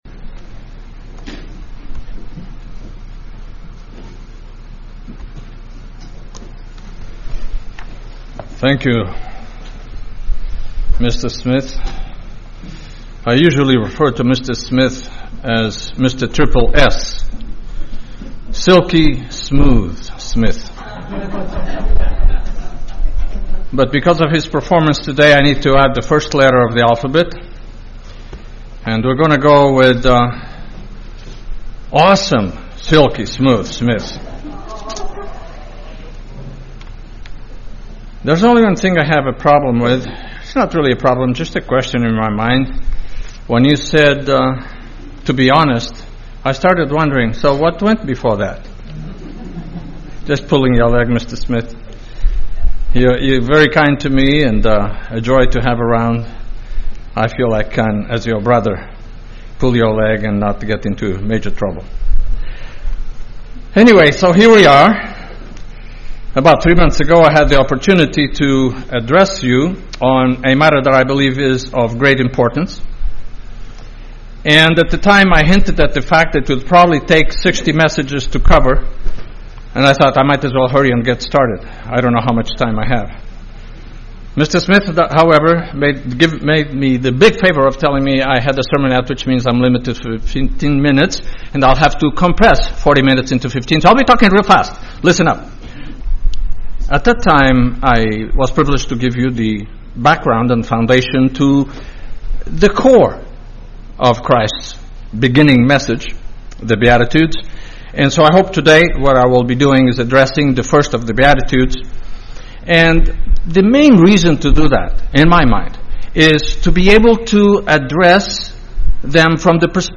Sermons
Given in Honolulu, HI